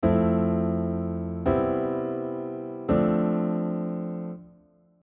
backcycling - chord progression.mp3